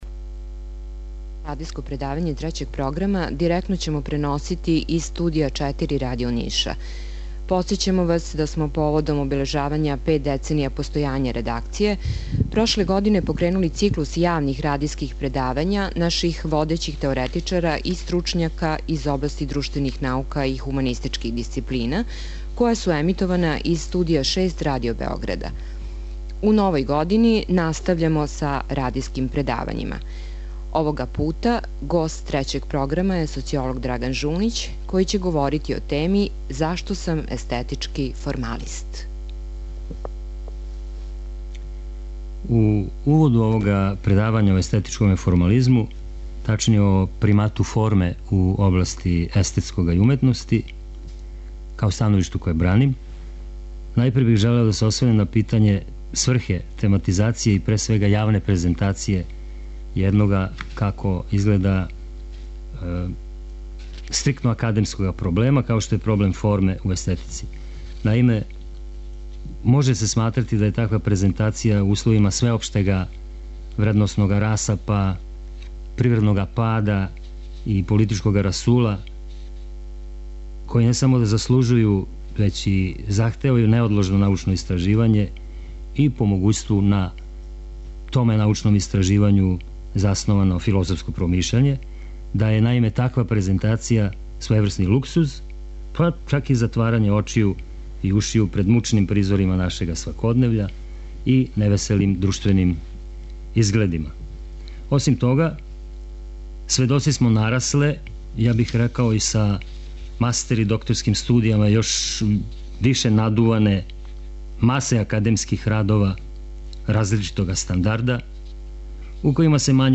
Директан пренос из Студија 4 Радио Ниша